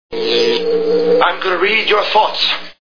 Back to the Future Movie Sound Bites